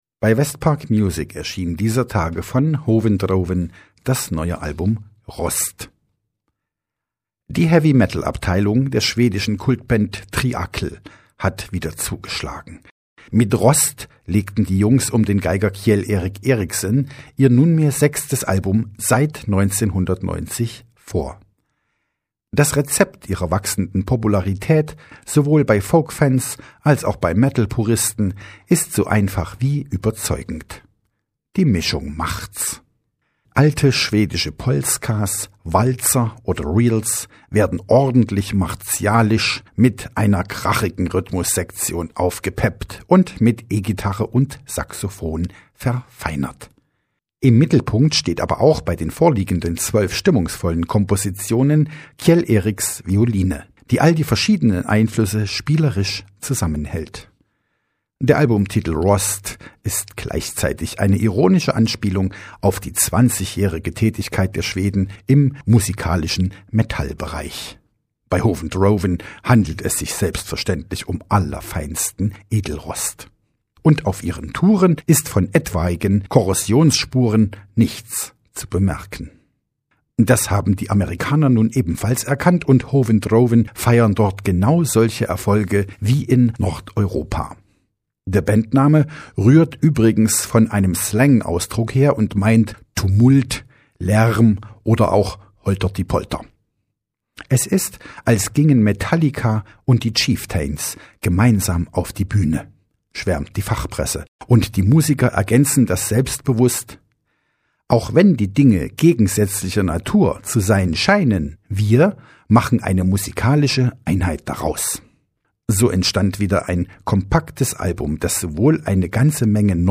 Heavy metal goes folk oder auch andersherum.